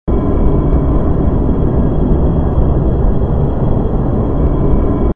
hum_tl_ring.wav